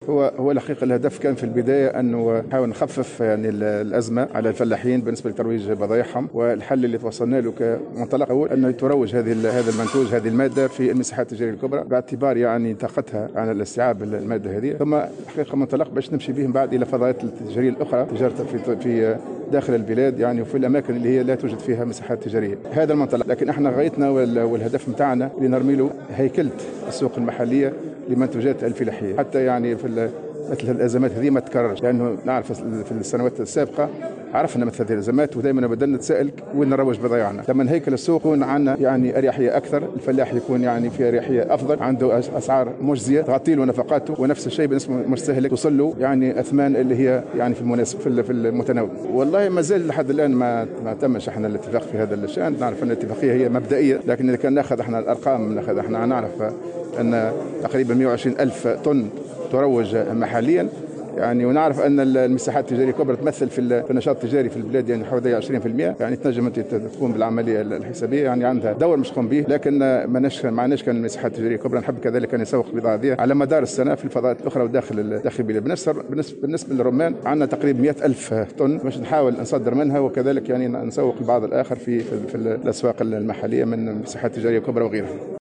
قال وزير التجارة محمد بوسعيد في تصريح لـ "الجوهرة أف أم" اليوم الاثنين إن الهدف من توقيع اتفاقية حول ترويج التمور والرمان هو التخفيف من تداعيات الأزمة على الفلاحين وتمكينهم من ترويج بضاعتهم بالمساحات التجارية الكبرى وداخل البلاد التي لا توجد فيها مساحات تجارية، وفق تعبيره.